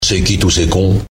PLAY Money SoundFX